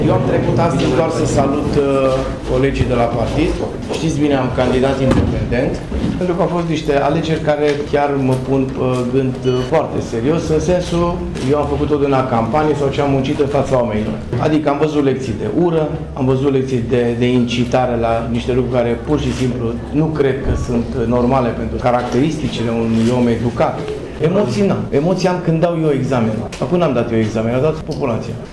Candidatul independent susţinut de PNL a declarat azi noapte la sediul liberalilor din Tîrgu-Mureş, că nu a avut emoţii la aceste alegeri, însă a fost pus pe gânduri de unele incitări la ură făcute de contracandidaţi ai săi, fără să nominalizeze vreo persoană: